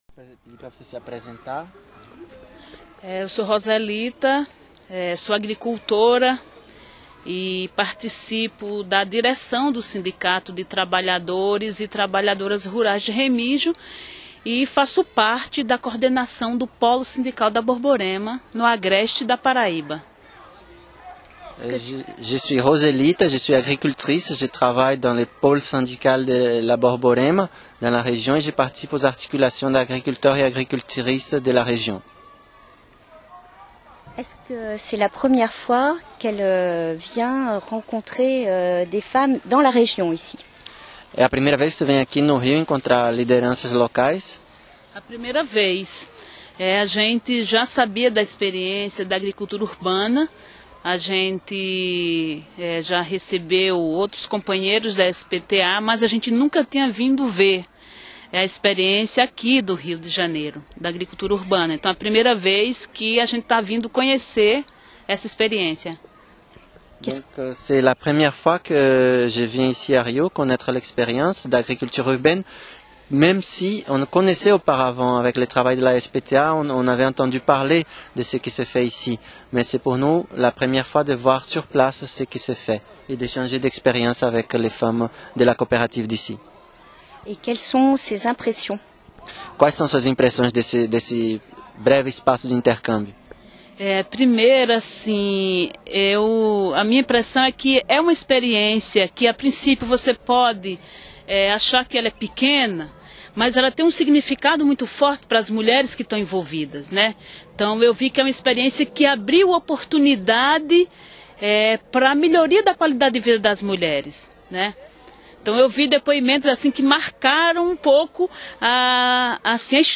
Voici trois interviews  audio de femmes agricultrices dans un quartier défavorisé de la grande banlieue de Rio.